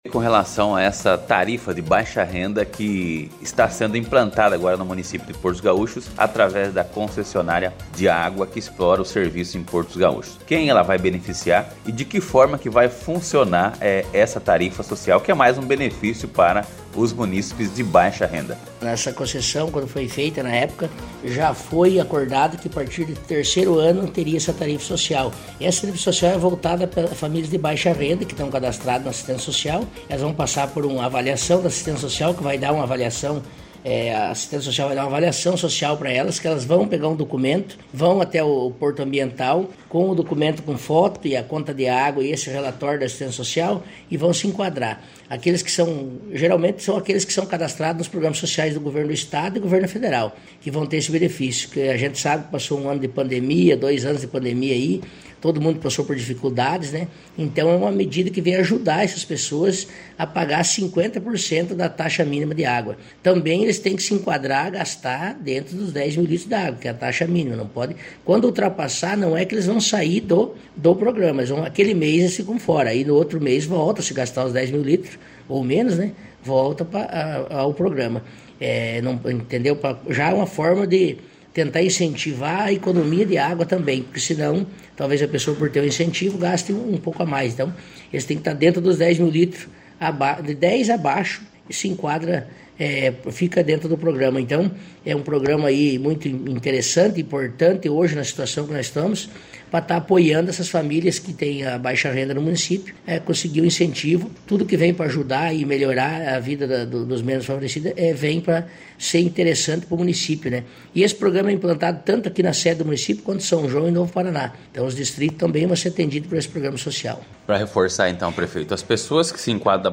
A partir de agora essas pessoas cadastradas em programas sociais já podem procurar a secretaria de Assistência Social para a avaliação e terem o relatório em mãos pra levar até a Porto Ambiental”, concluiu o prefeito Vanderlei.